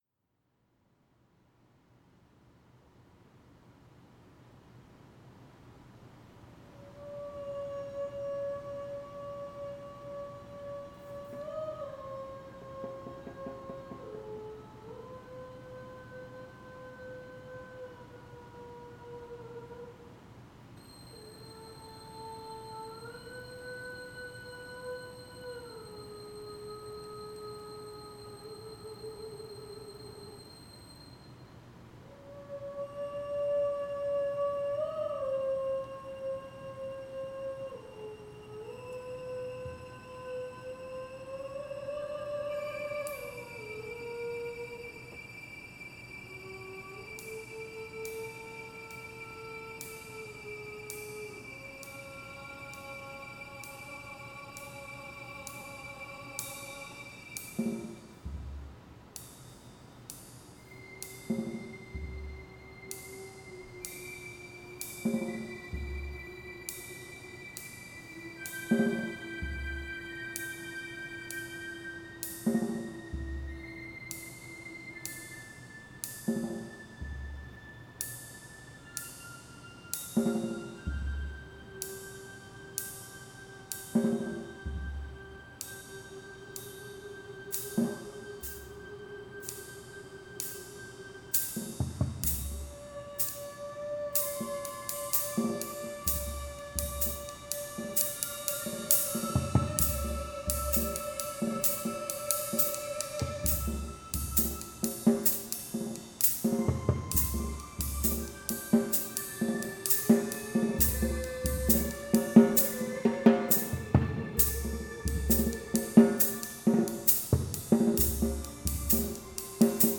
Rehearsal Arts Mission